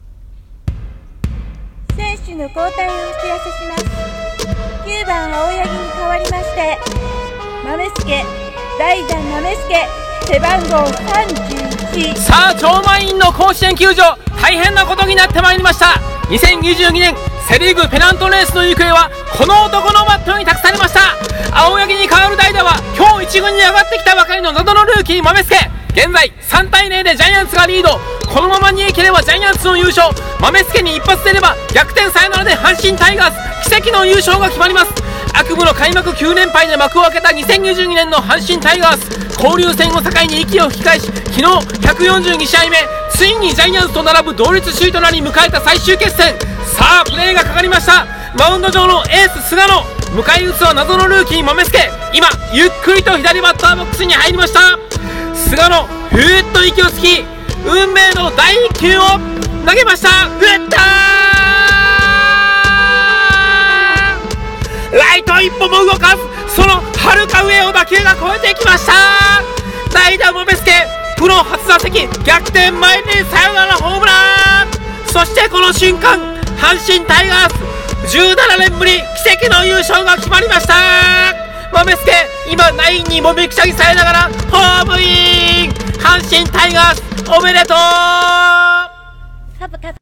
さんの投稿した曲一覧 を表示 コラボ用2022年阪神タイガース優勝架空実況